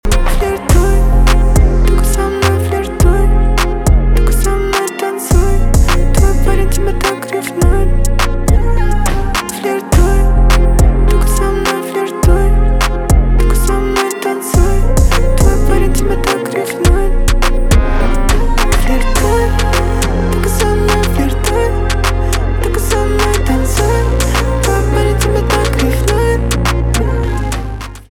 Крутая песня с битовыми басами, идеально подходит на звонок.
rnb
битовые басы